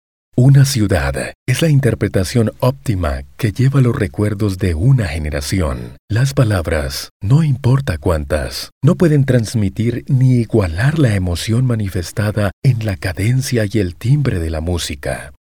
西班牙语样音试听下载